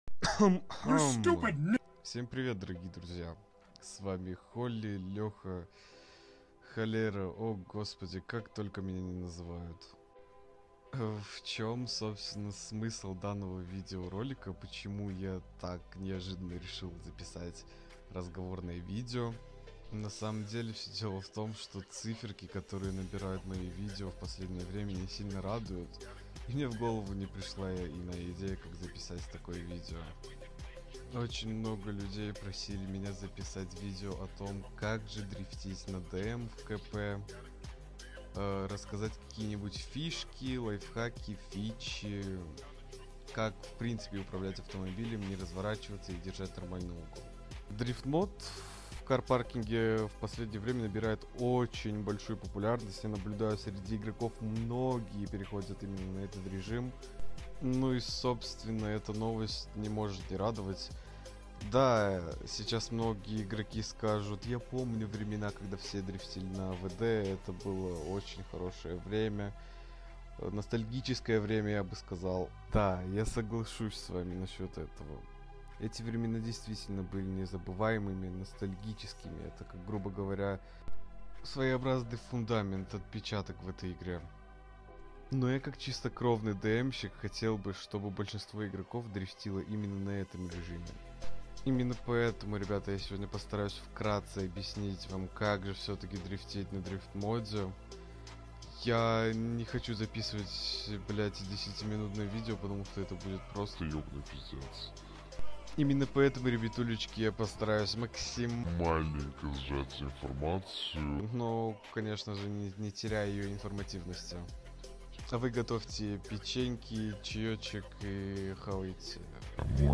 Извиняюсь за тихий звук🔨.